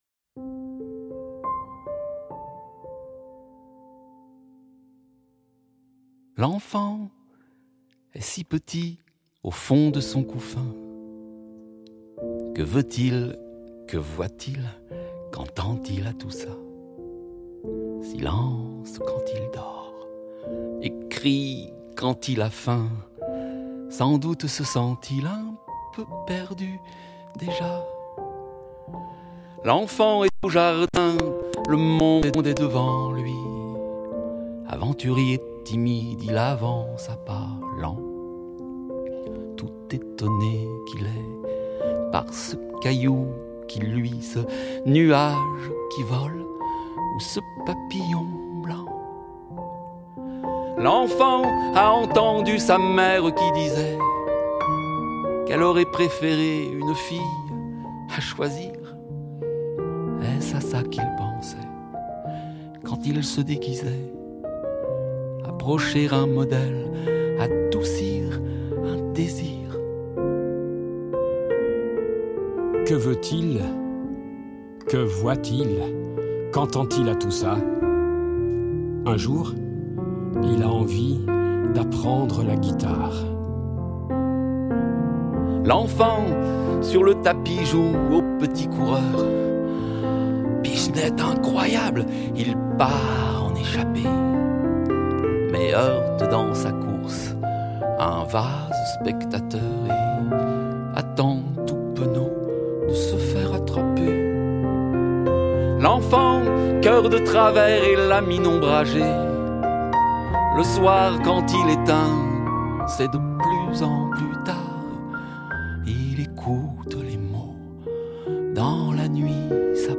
chant
piano